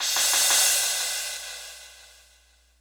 crash02.wav